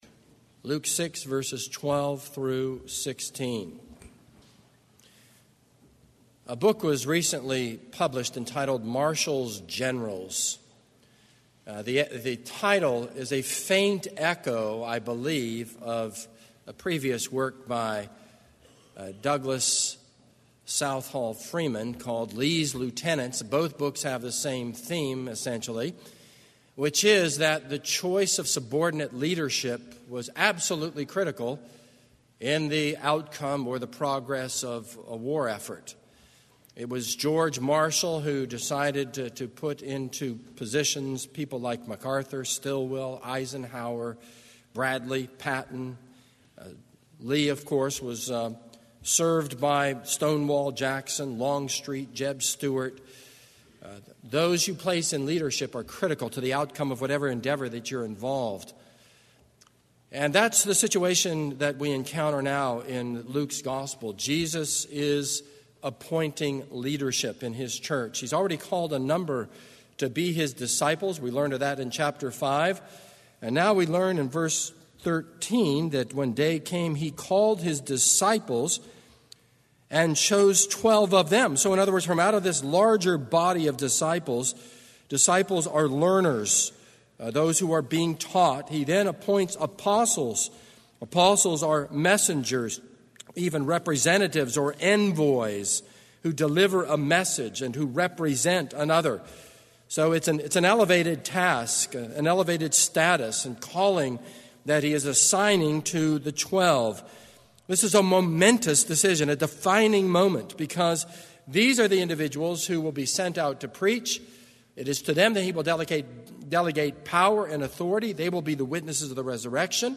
This is a sermon on Luke 6:12-16.